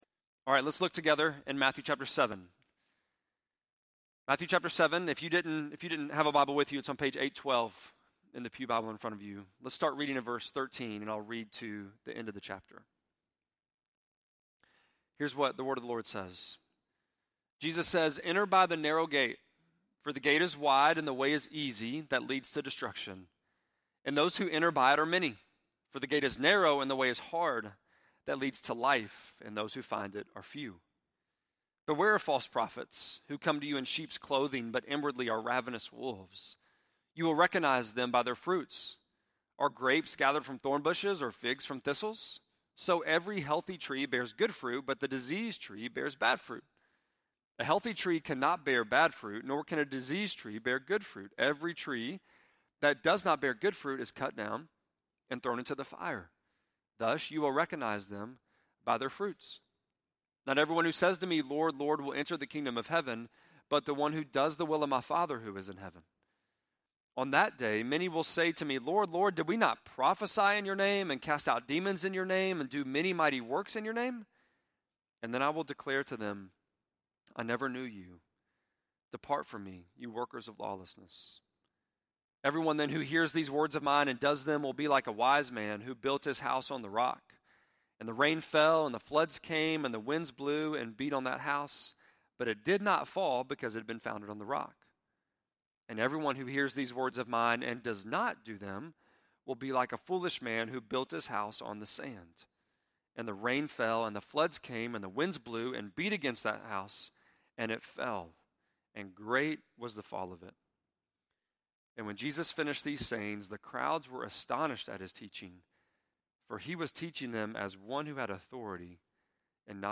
5.26-sermon.mp3